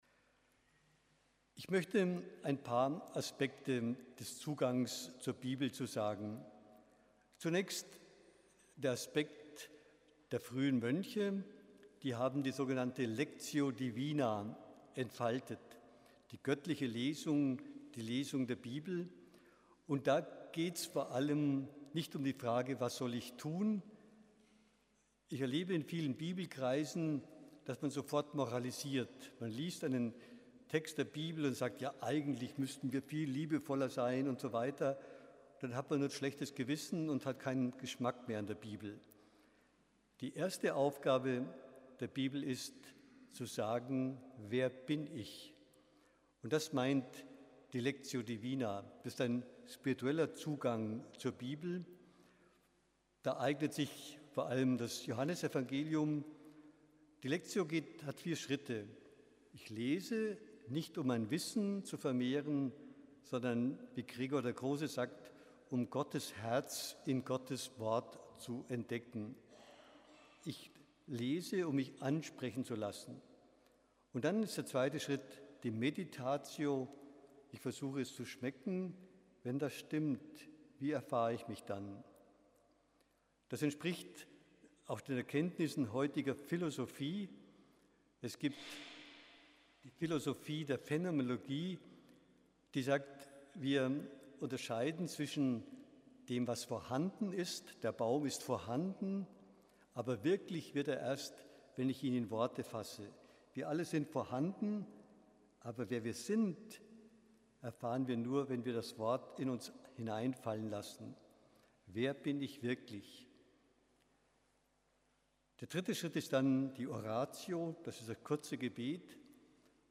speechfragmentlectiodivinaanselmgrun.mp3